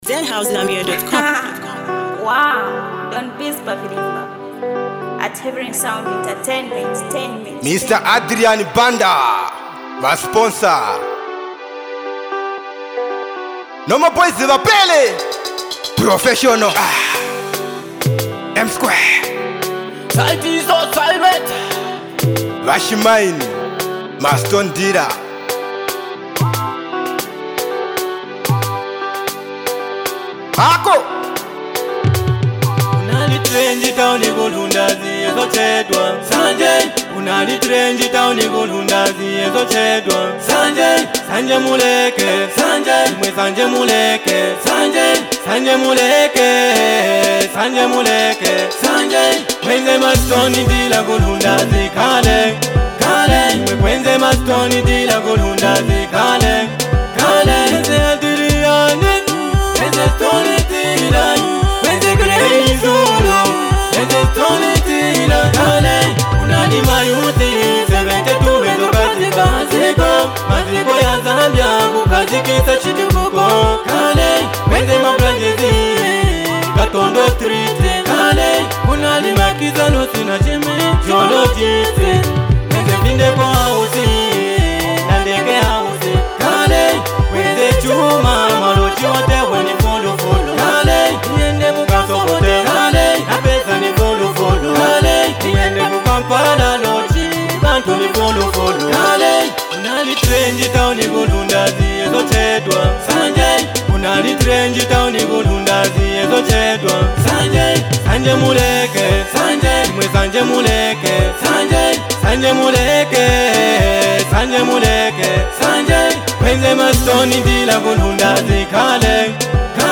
With intense lyrics and a raw beat